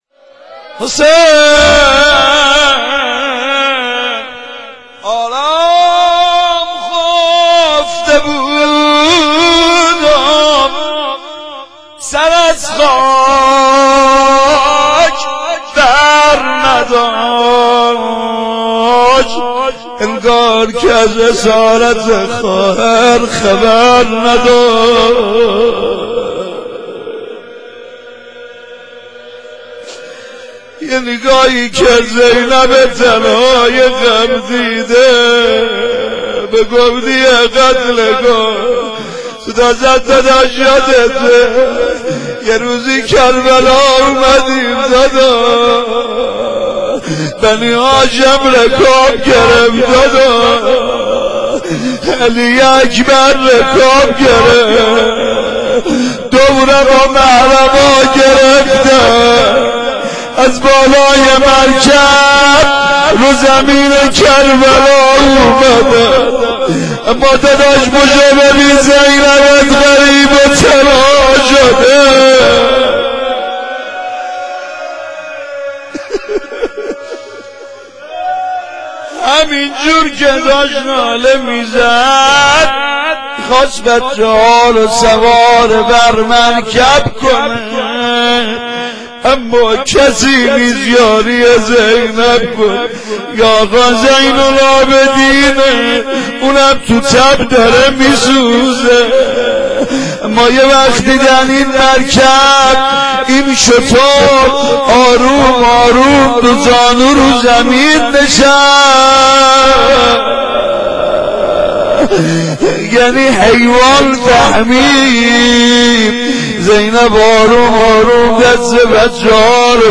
بخش چهارم روضه
وفات حضرت زینب (س)